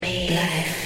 just_plain_scary.mp3